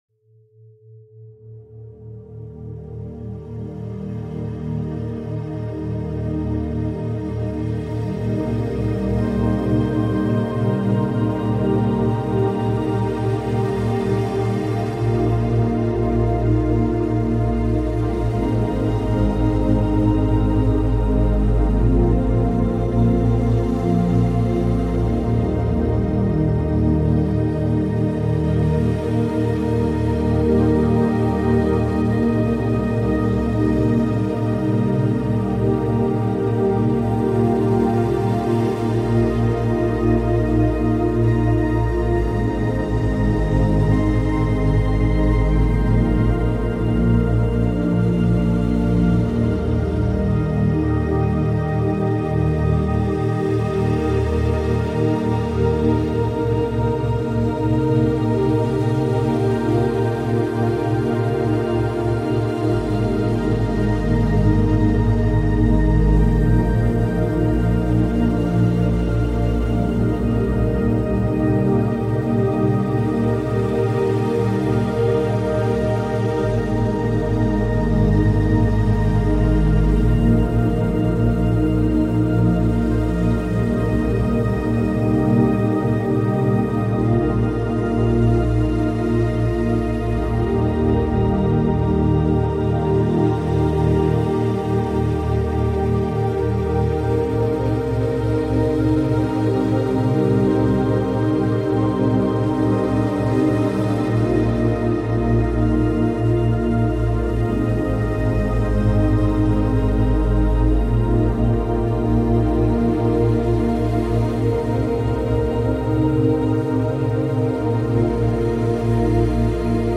Hiver paisible longue durée · méthode neige et feu pour concentration profonde